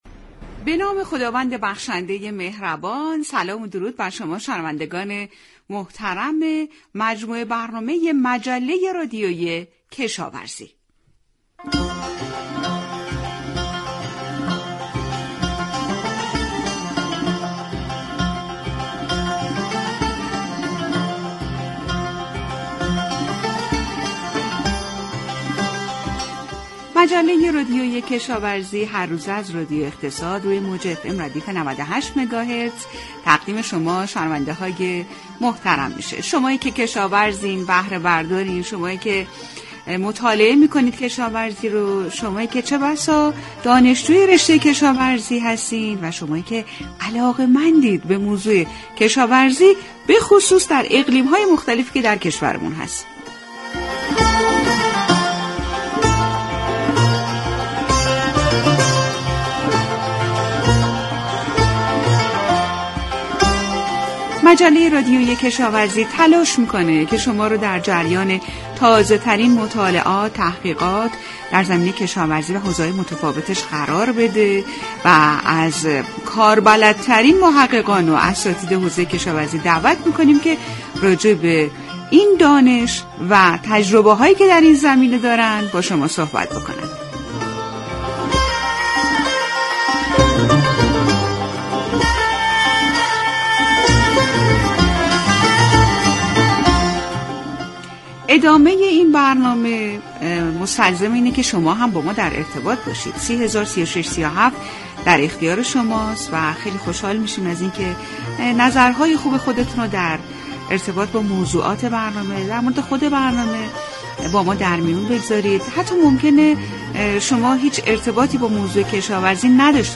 مصاحبه های علمی، فنی و ترویجی